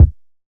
Kicks
DillaOldKick.wav